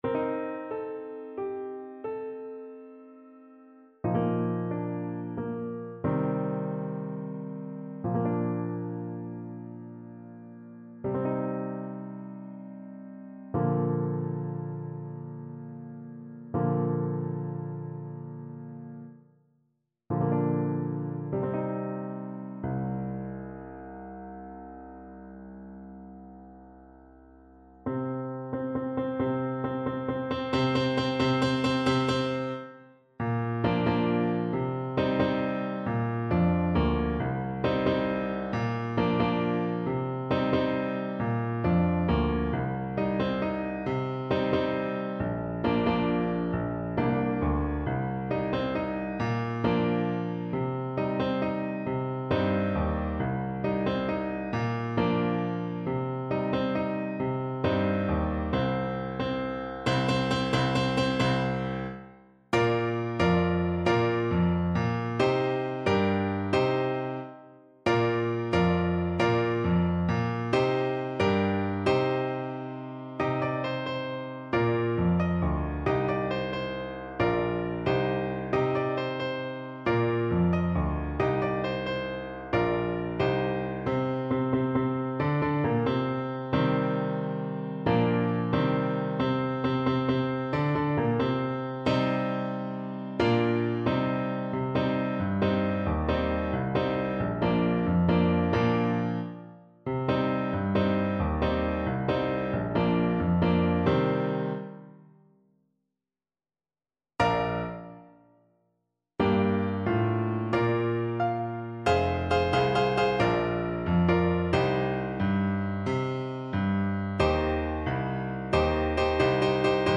Alto Saxophone
Traditional Music of unknown author.
6/8 (View more 6/8 Music)
C minor (Sounding Pitch) A minor (Alto Saxophone in Eb) (View more C minor Music for Saxophone )
Slow .=c.80
Traditional (View more Traditional Saxophone Music)
Pakistani